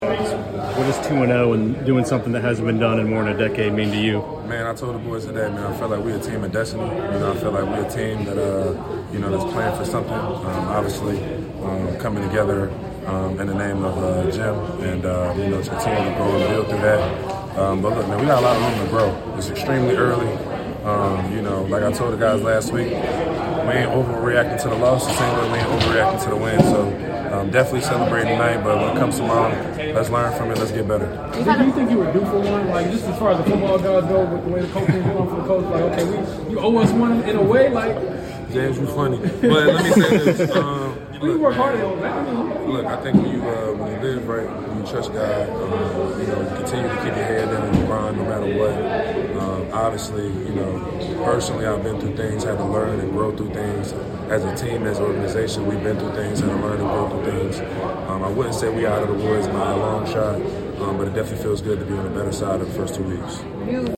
Indianapolis Colts Linebacker Zaire Franklin Postgame Interview after defeating the Denver Broncos at Lucas Oil Stadium.